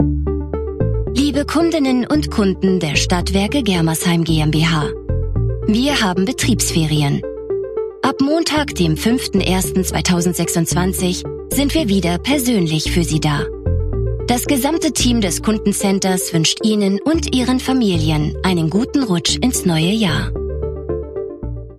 Booking Sprecherin